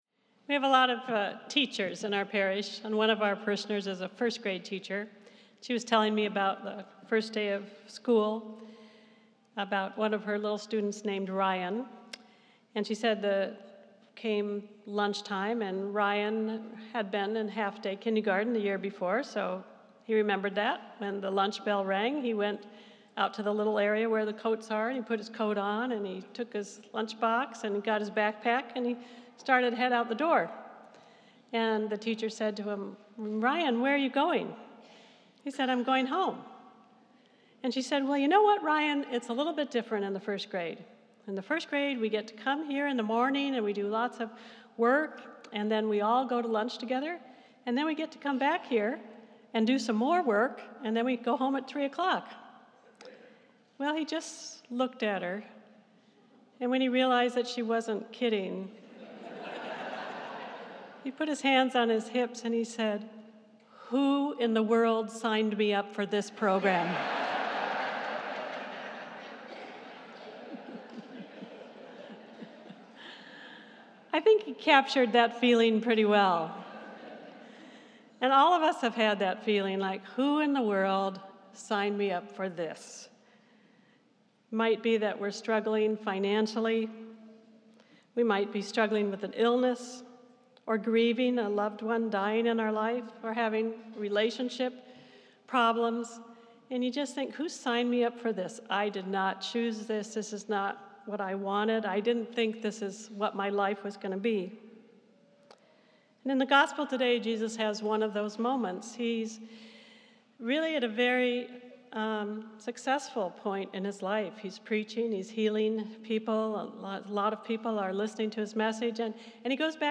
This is a Sunday morning mass at Spiritus Christi Church in Rochester, NY.